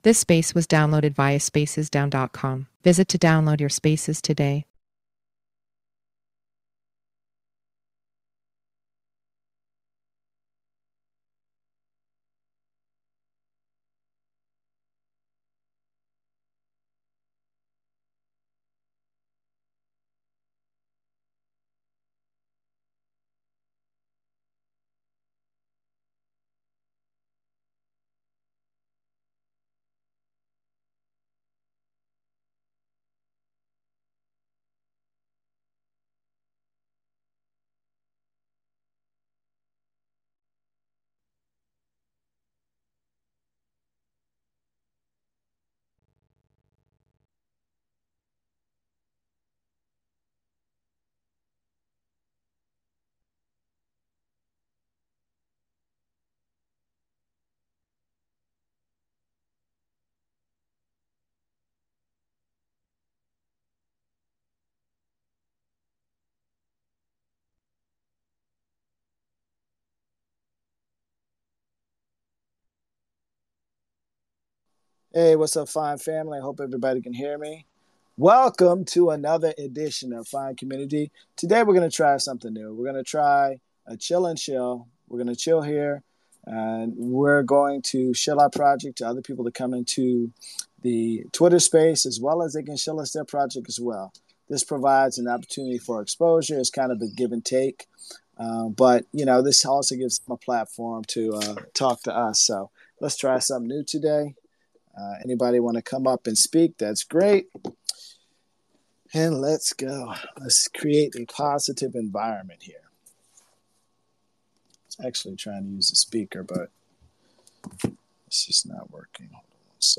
$FINE ERC Telegram Voice Chat and Twitter Spaces Archive